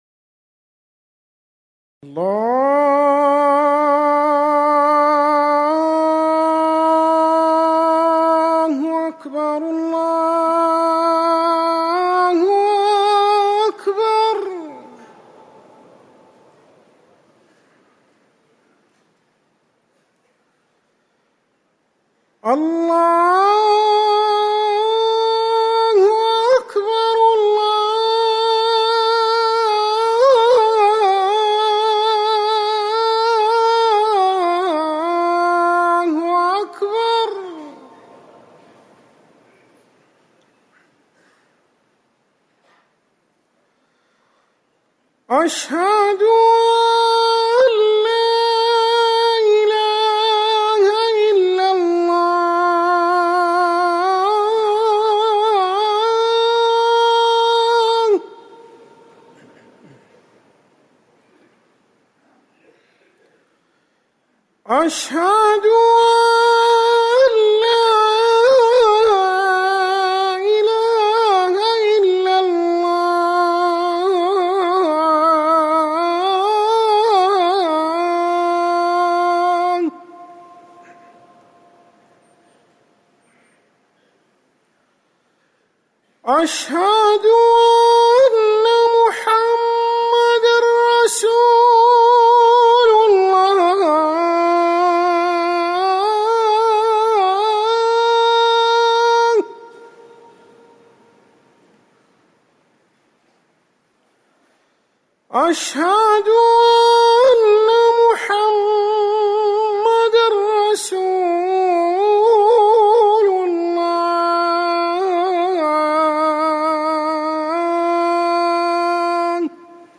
أذان العصر - الموقع الرسمي لرئاسة الشؤون الدينية بالمسجد النبوي والمسجد الحرام
تاريخ النشر ٢٤ صفر ١٤٤١ هـ المكان: المسجد النبوي الشيخ